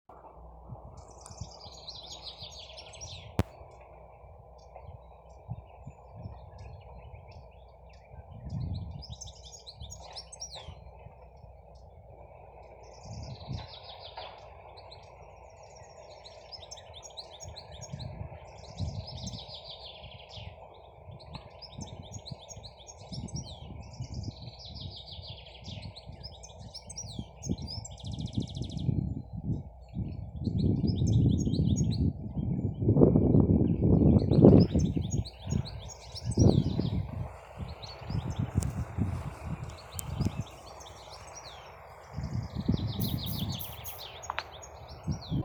щегол, Carduelis carduelis
Ziņotāja saglabāts vietas nosaukumsVecumnieku pag. Valle
СтатусПоёт